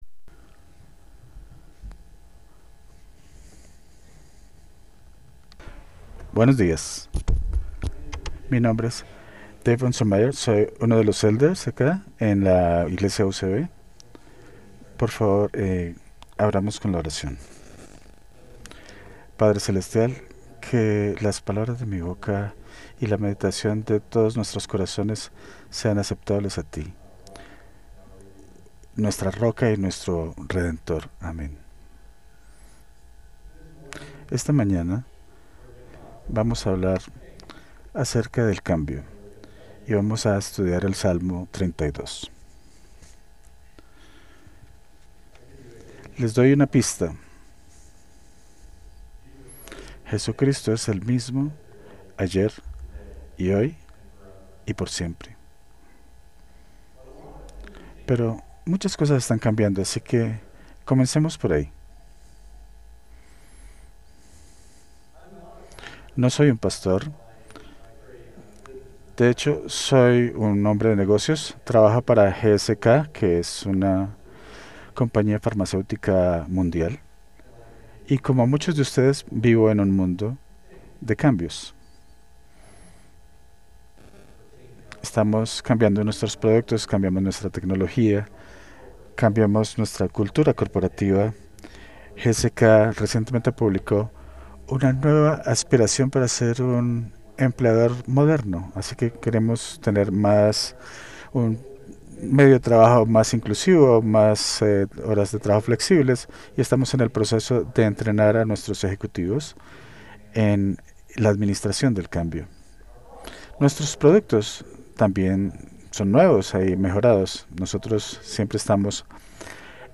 Spanish Sermons